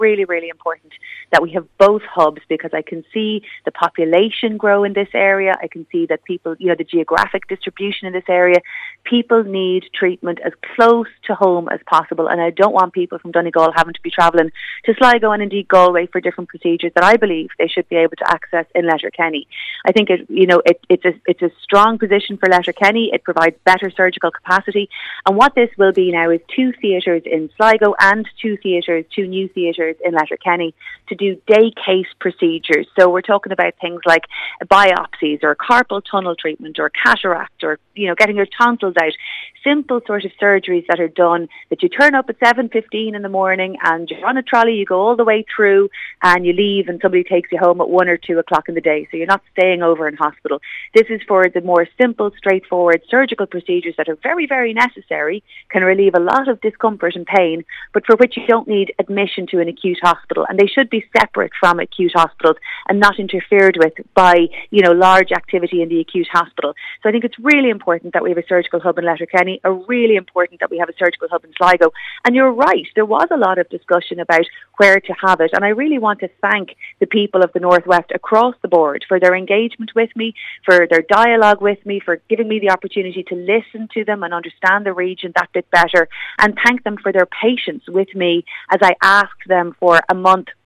She says the hubs will facilitate day services: